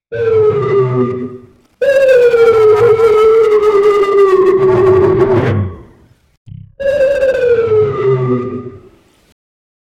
crazy-alien-yelp-short-vi-wcciw56u.wav